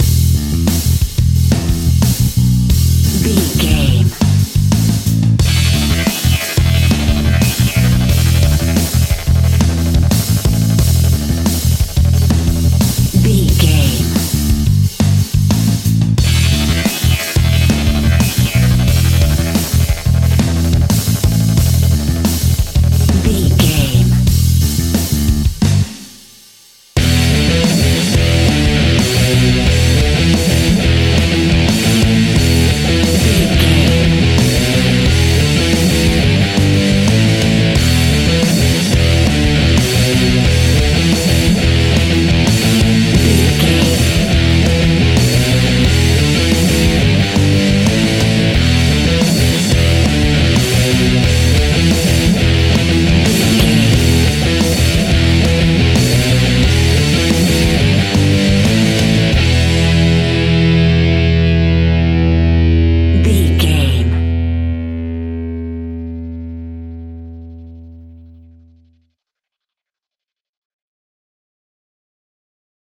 Hard and Powerful Metal Rock Music Cue 60 Sec.
Epic / Action
Aeolian/Minor
hard rock
heavy metal
Rock Bass
heavy drums
distorted guitars
hammond organ